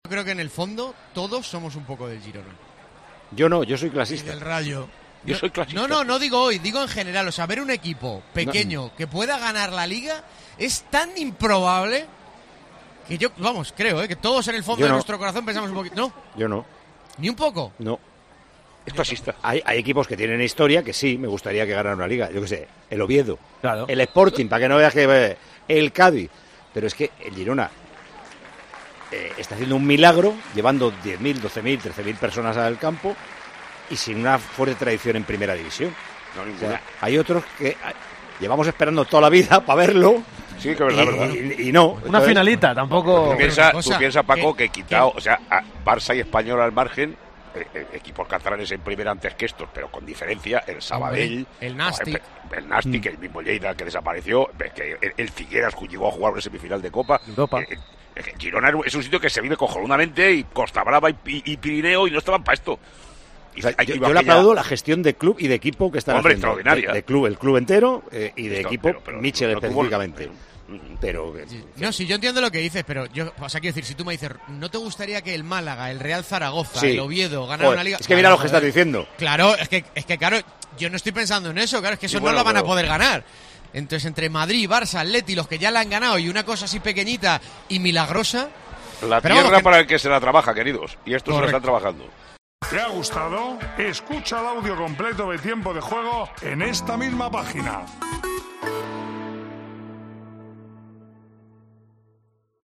El director y presentador de Tiempo de Juego da su opinión sobre el equipo revelación de este año en Primera División.